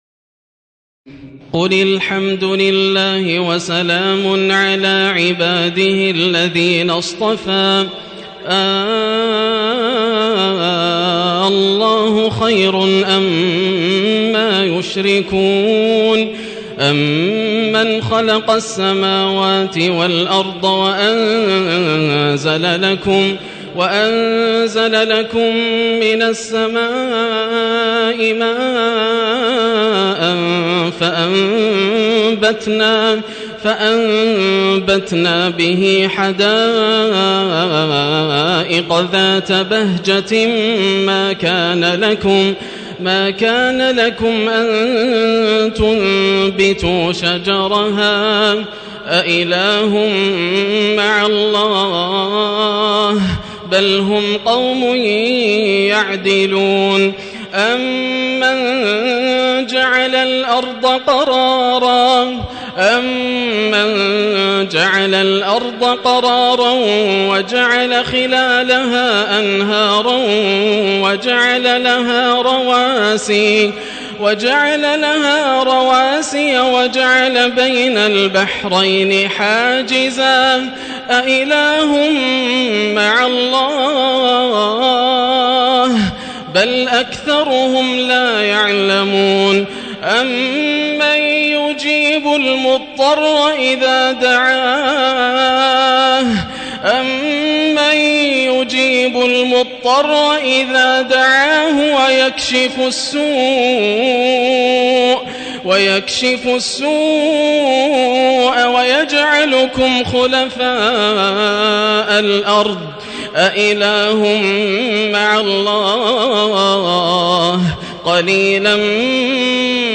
تراويح الليلة التاسعة عشر رمضان 1437هـ من سورتي النمل(59-93) و القصص(1-50) Taraweeh 19 st night Ramadan 1437H from Surah An-Naml and Al-Qasas > تراويح الحرم المكي عام 1437 🕋 > التراويح - تلاوات الحرمين